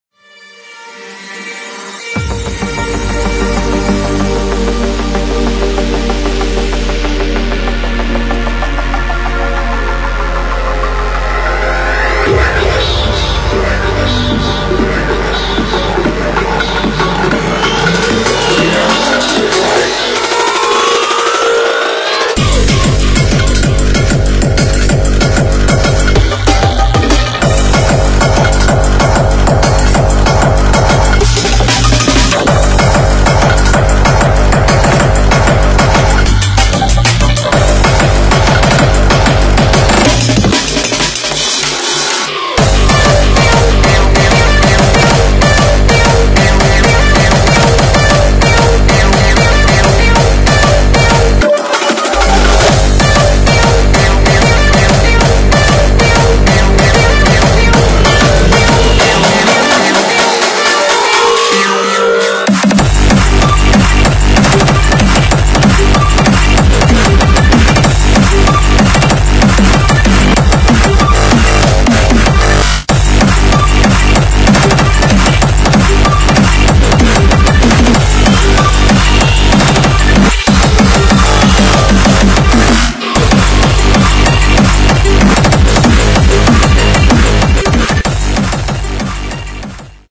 [ HARDCORE / DRUM'N'BASS ]